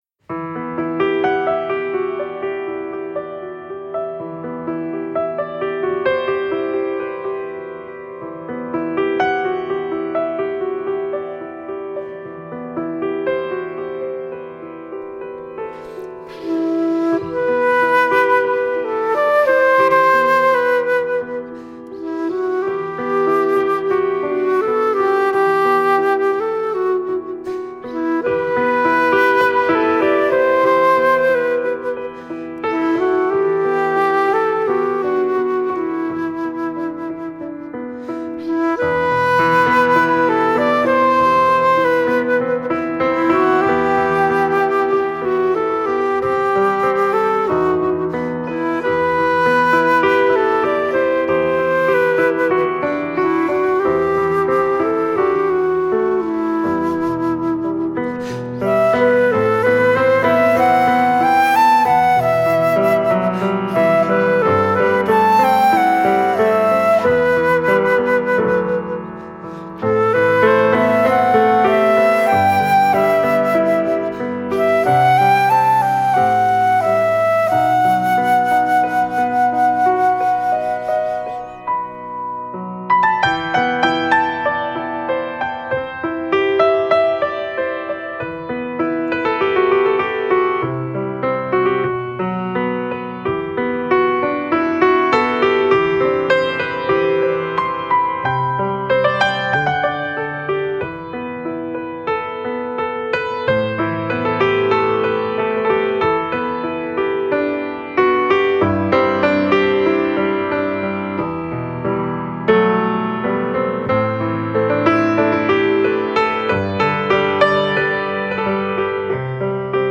同时，他也是一个真正的音乐大使，他的风格融合了亚洲、凯尔特、中东、拉丁美洲、古典和爵士乐，体现了不同的文化、传统和经验。